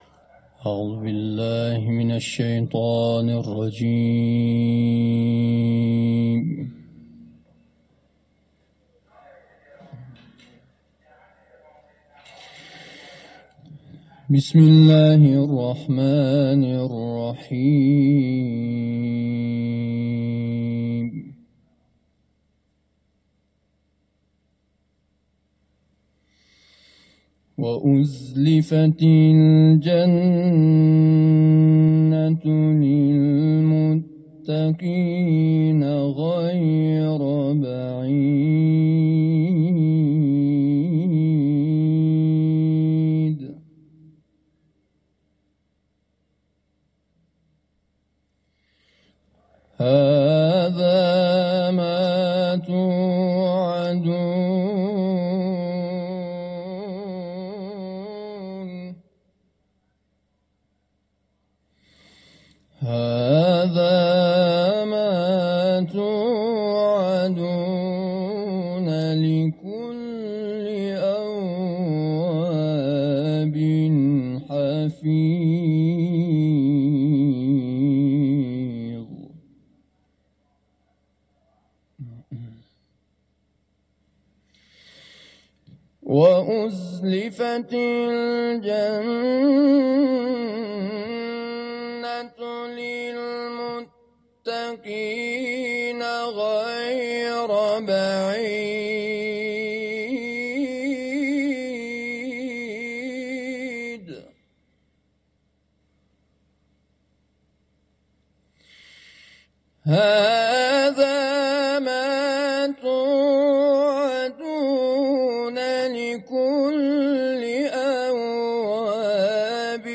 در حرم رضوی
تلاوت